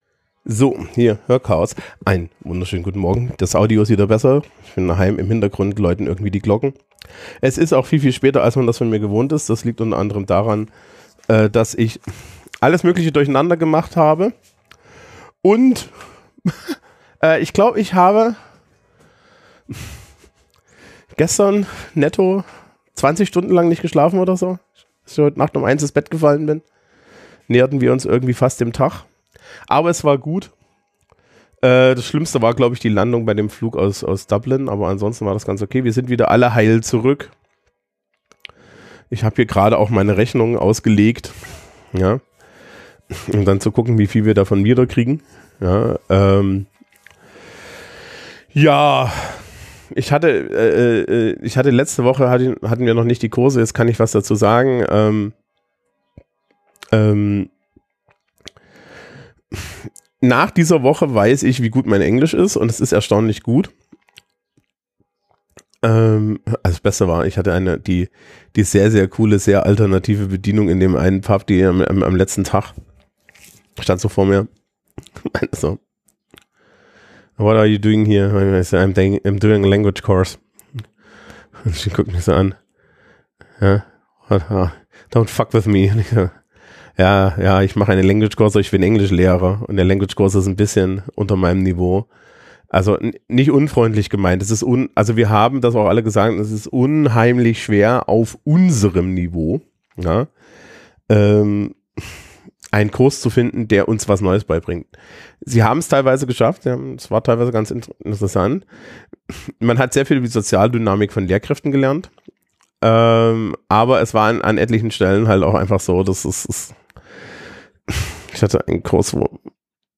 Ich bin aus Dublin zurück, nach einer Party und tiefenentspannt, wie man merkt.